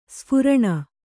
♪ sphuraṇa